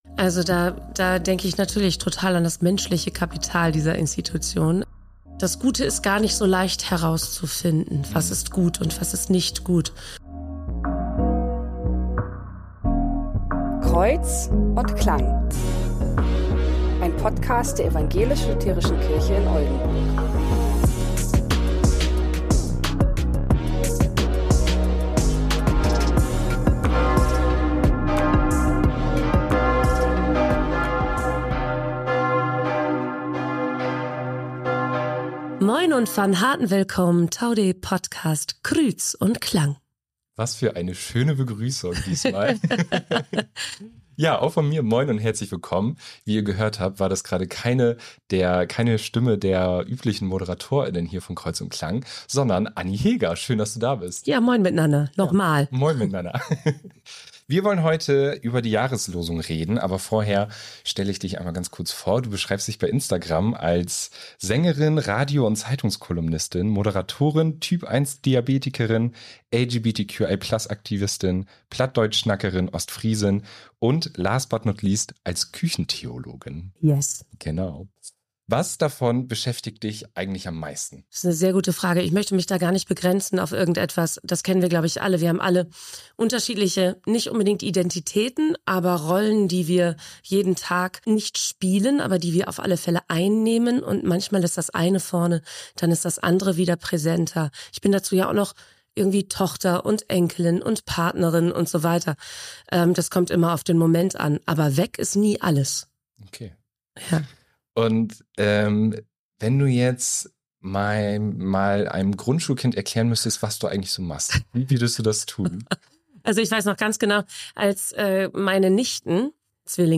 Kreuz & Klang ist ein Podcast der Ev.-Luth. Kirche in Oldenburg.